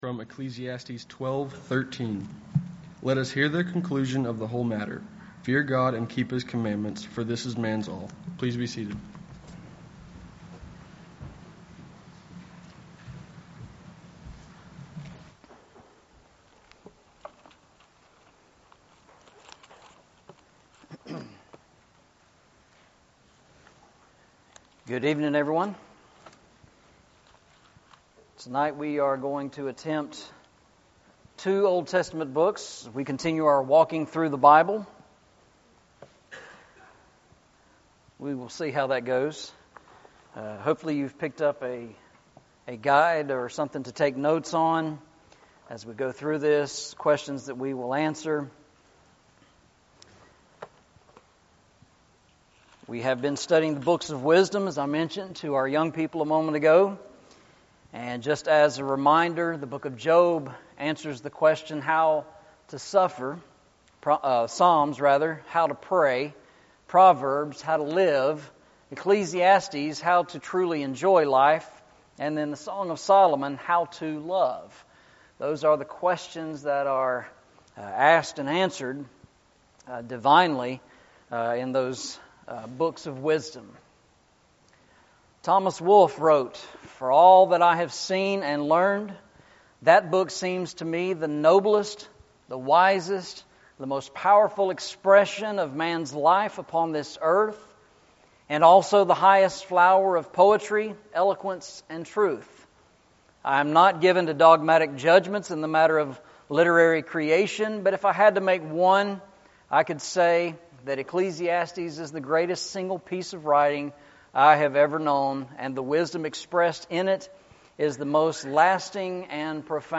Eastside Sermons
Service Type: Sunday Evening